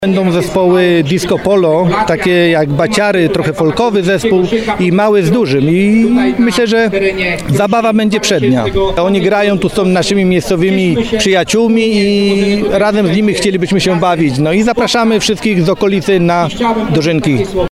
Trwa święto plonów w Krośniewicach.